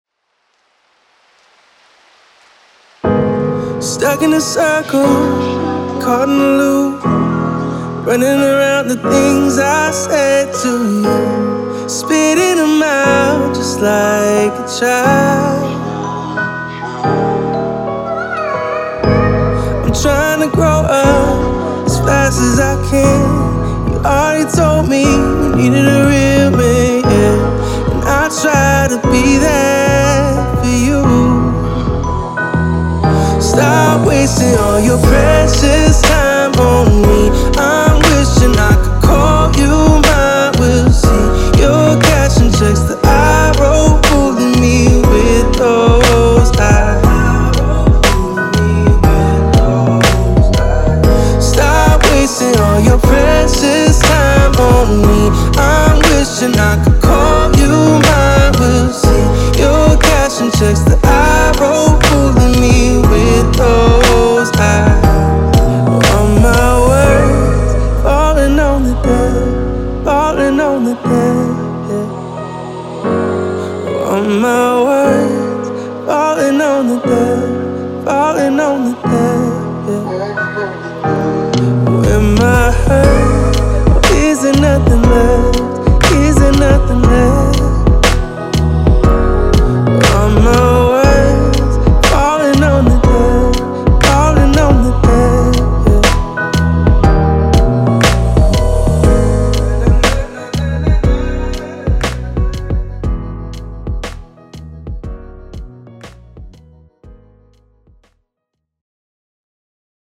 它包含来自7位专业歌手22种全无伴奏合唱，适用于各种音乐风格。